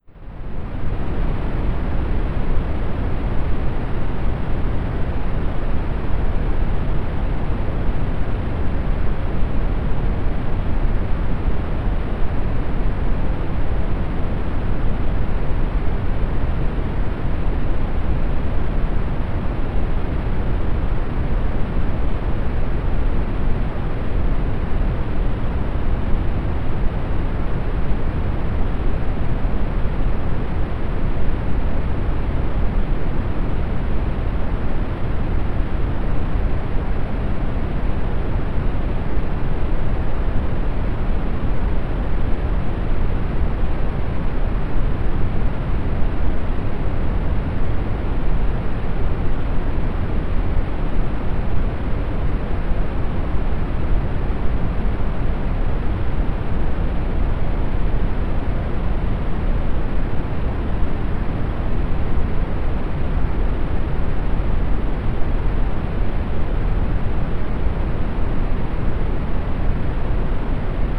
earthquake.wav